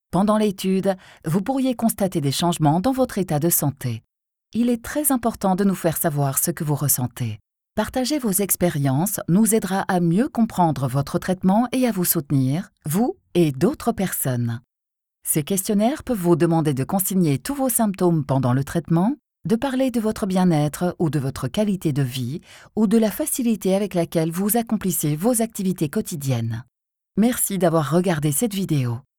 Warm, Diep, Vertrouwd, Volwassen, Zakelijk
E-learning